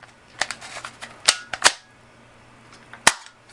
手枪重装弹药上膛音效
描述：TOKYO Marui HiCapa 5.1手枪
标签： 气枪 武器 手枪 音效 重装 上膛
声道立体声